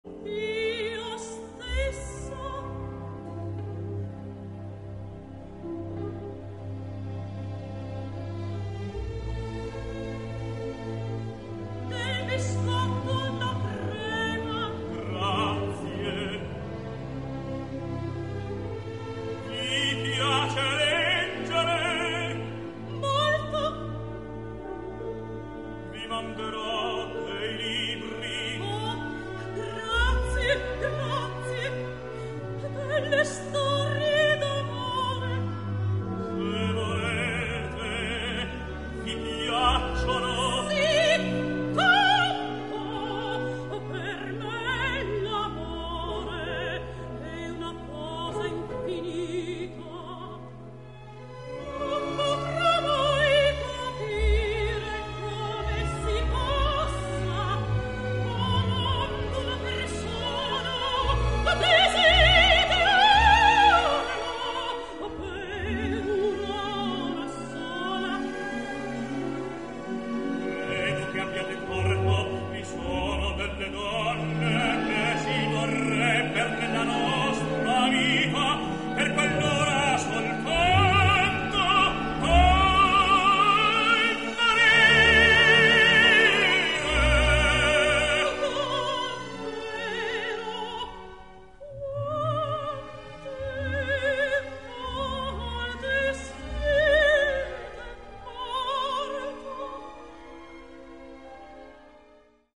Minnie Falconer [Sopran]
Dick Johnson [Tenor]